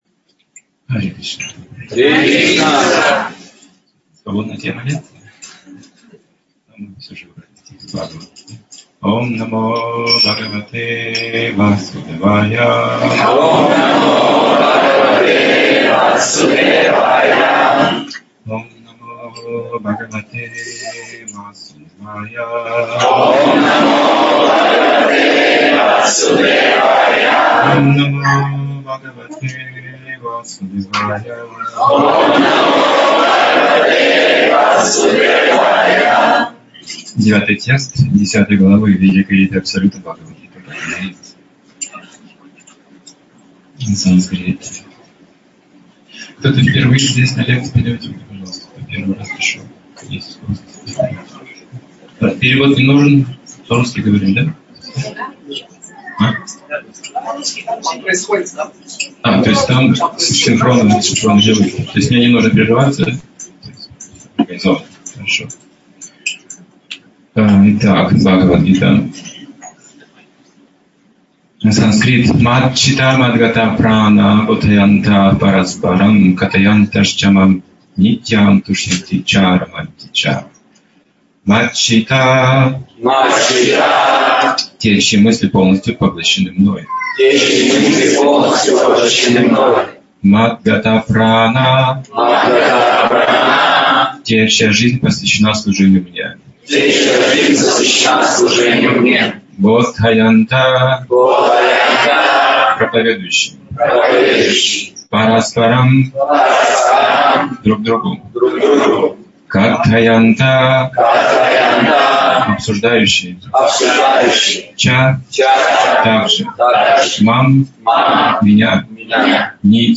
Темы, затронутые в лекции: Различные типы вер Бхагавад-гита - как основа философии Все поддерживающая энергия Господа Свобода выбора Узы кармической деятельности Освобождение через служение Признаки века Кали Тайна преданного служения Диалектика взаимоотношений Познание себя